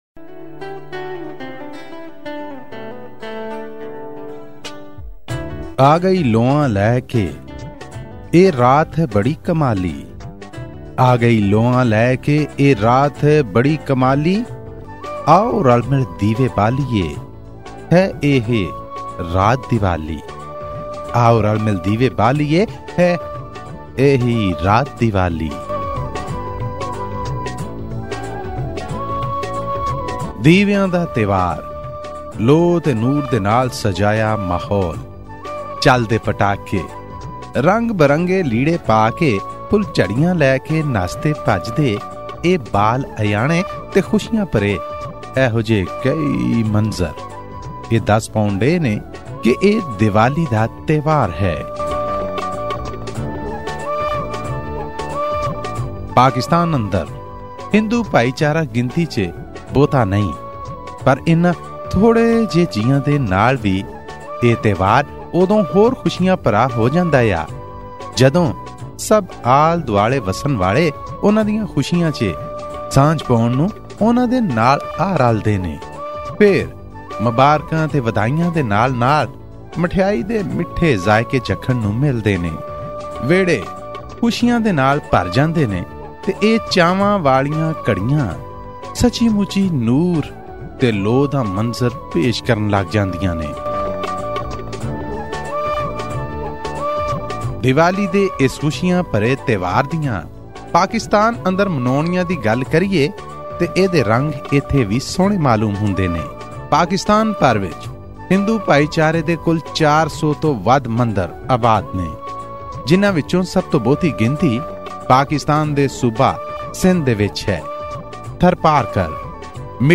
Tune into this special report by our Lahore-based correspondent on Diwali celebrations in Pakistan.